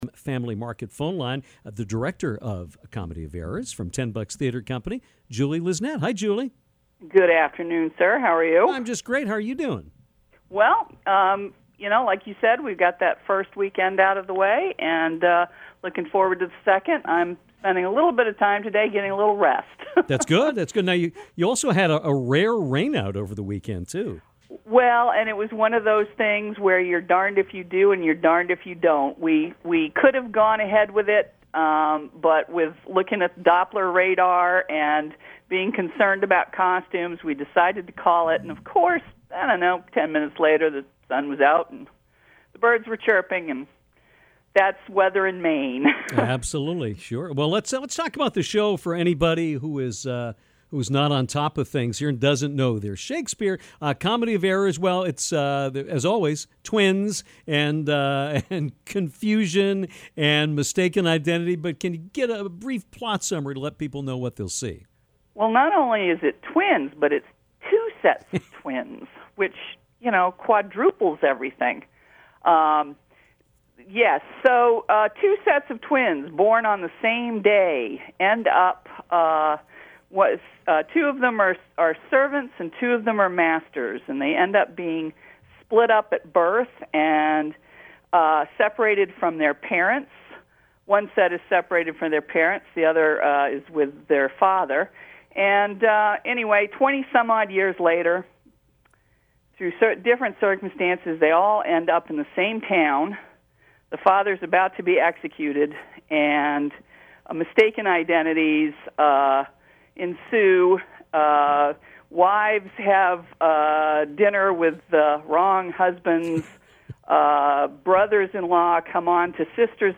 called into the Downtown show on Monday afternoon